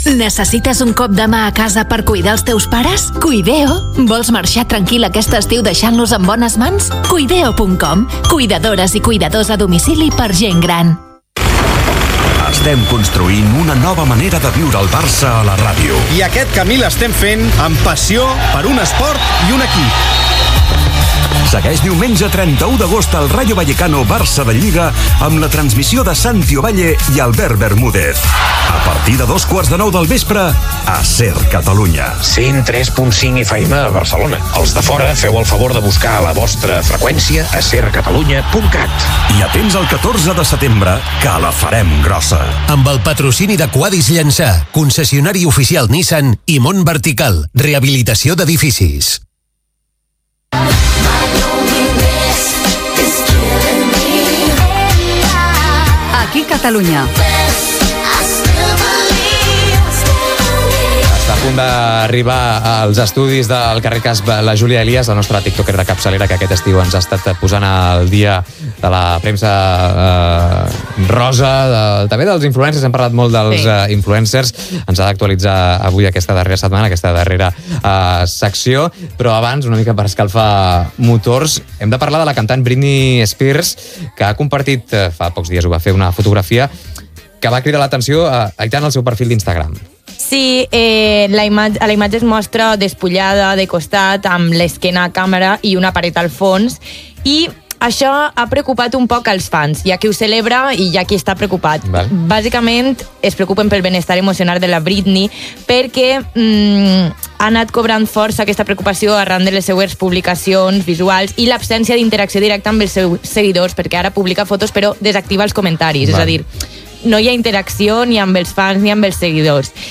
Publicitat, promoció de "Què t'hi jugues Barça", comentari sobre la fotografia de la cantant Britney Spears, estat del trànsit, indicatiu de SER Catalunya
Gènere radiofònic Info-entreteniment